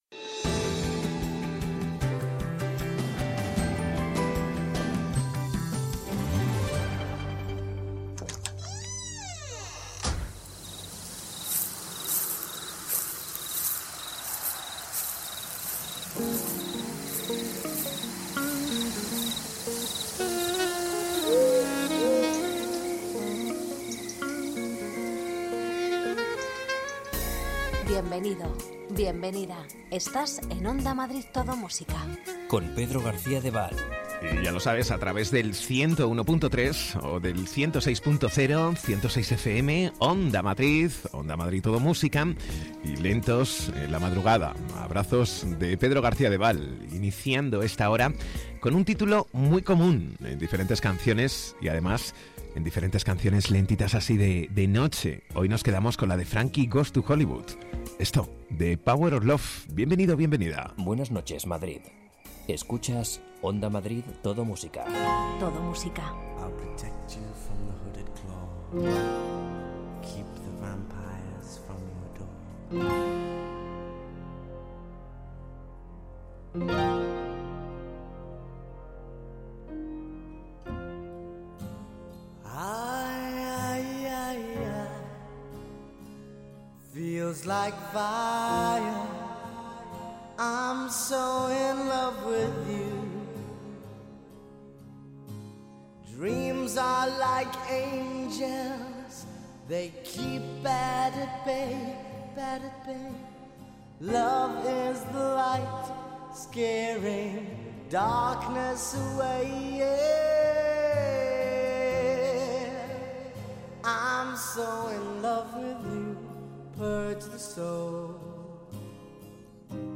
Ritmo tranquilo, sosegado, sin prisas.